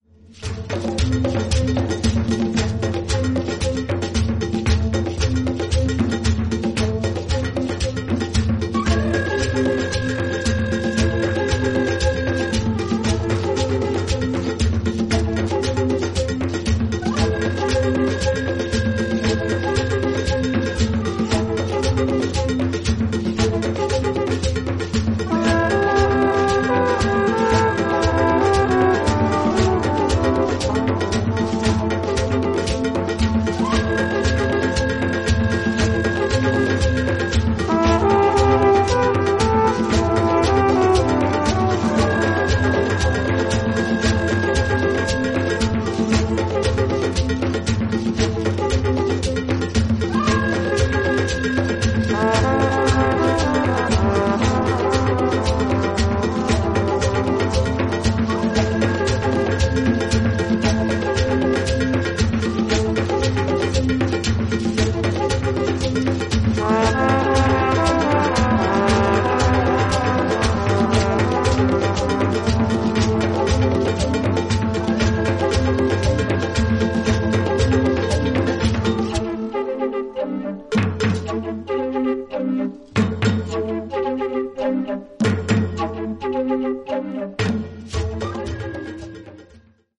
プリミティブなトライバル・ビートに乗る、浮遊感のあるホーンのメロディが印象的な